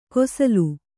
♪ kosalu